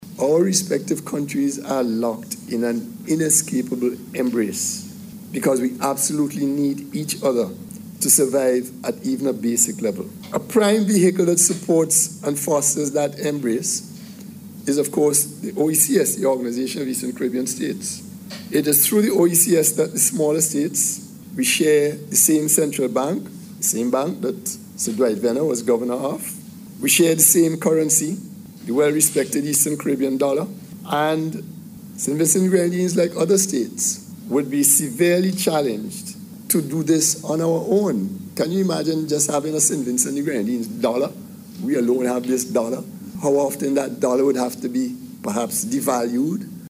Justice Saunders addressed the issue during the Sir Dwight Venner Independence Lecture last night at the UWI Global Campus.